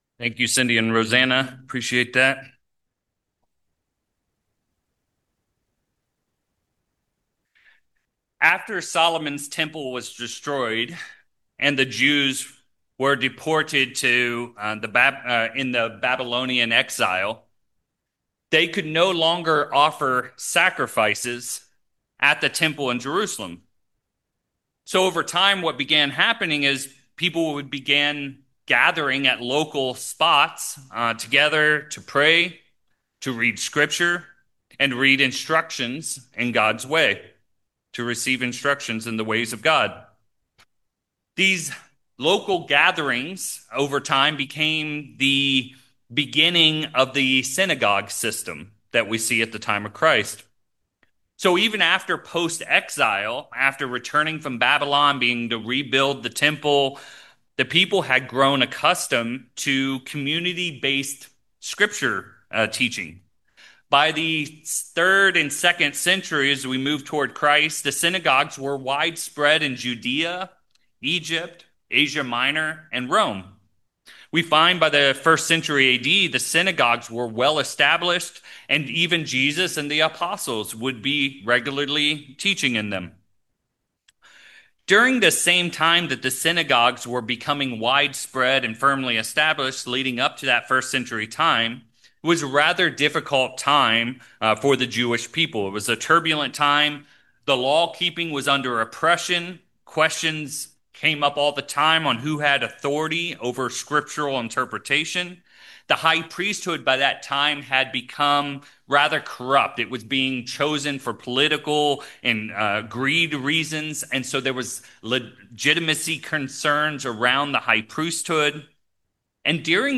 Summary: In this sermon we dive into the progression of self-righteousness, and then examine indications that self-righteousness has begun to grow in your life. We will finish by examining where our righteousness comes from and what it means for our lives.